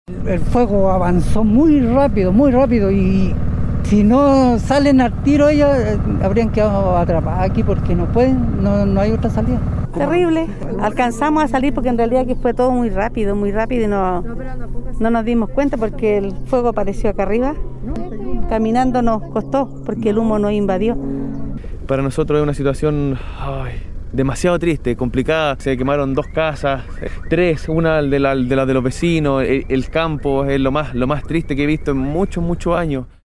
Por lo que creyeron los residentes del sector de Lo Marín, la emergencia no iba a afectar sus casas, pero la dirección del fuego cambió y quemó algunas de sus viviendas. Así lo relataron afectados.
402-cu-damnificados-caleu-ok.mp3